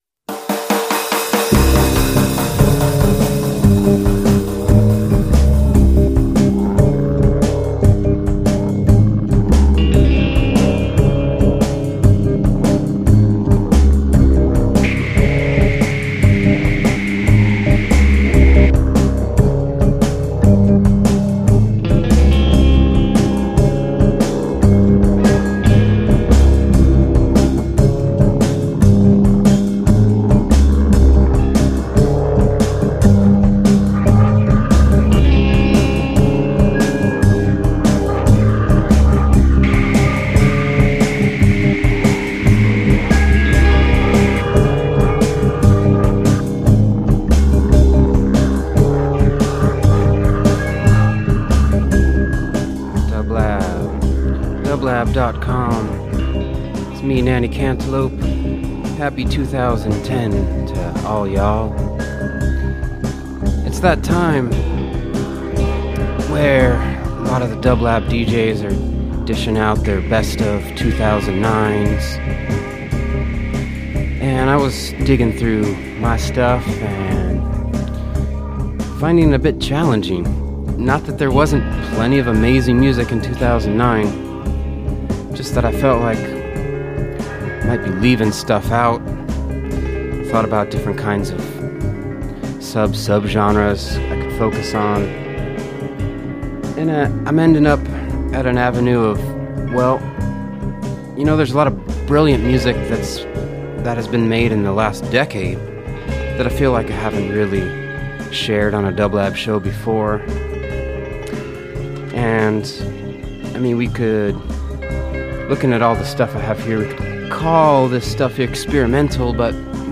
Hip Hop Rock